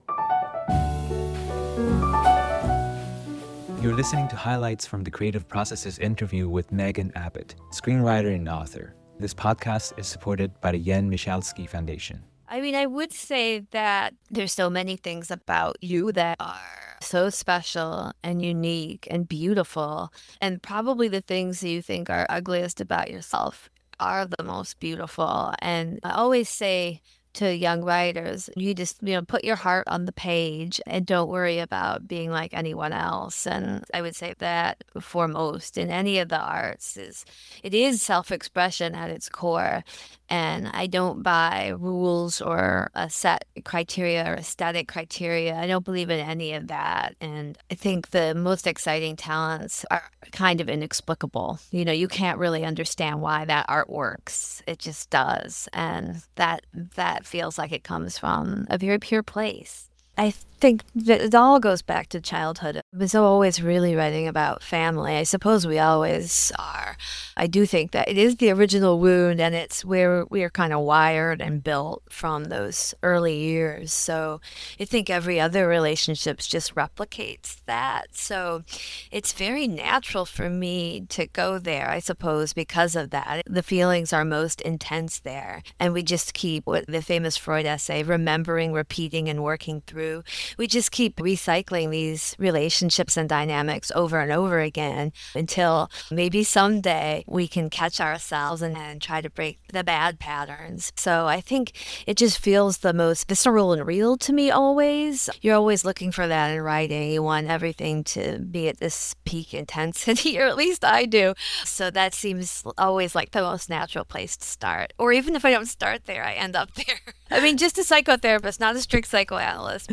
Conversations with writers, artists and creative thinkers across the Arts and STEM. We discuss their life, work and artistic practice.